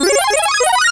Category:Sound effect media files
PM_Power-Up.wav.mp3